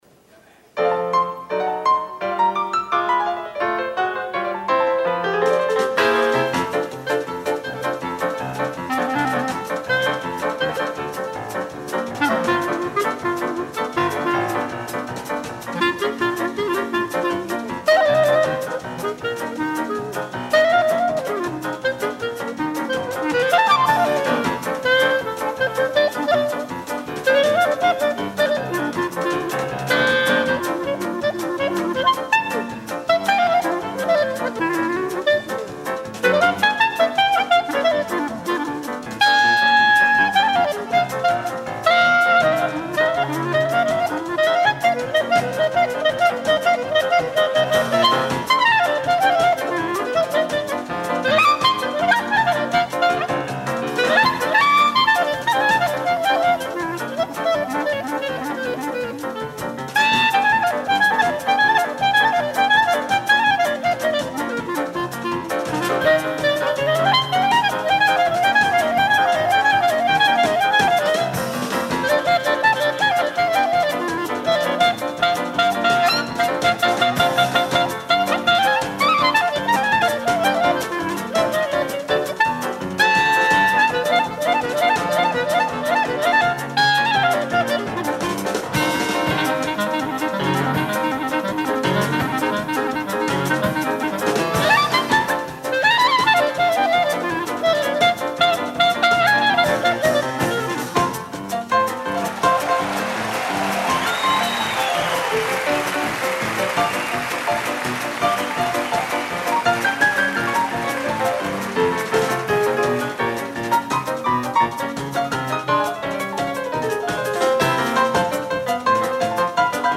clarinette, saxophone ténor
piano, saxophone alto
guitare
contrebasse
batterie